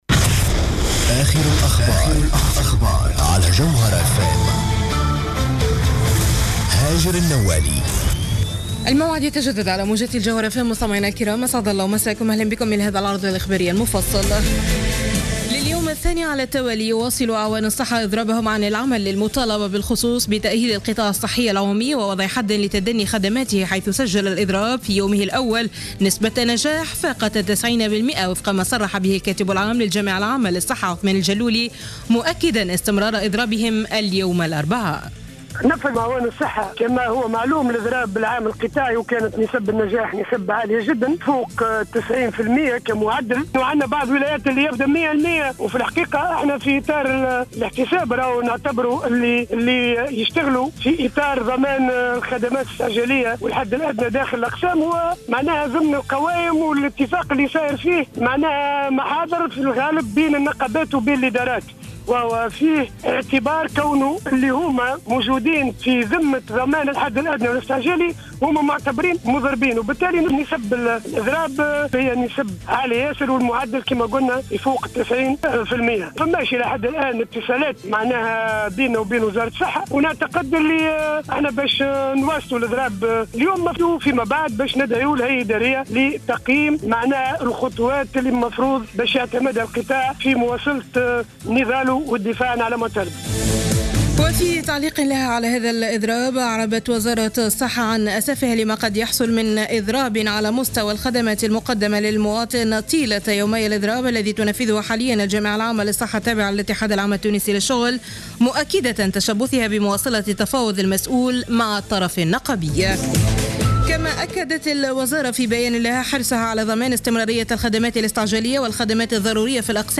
نشرة أخبار منتصف الليل ليوم الإربعاء 29 أفريل 2015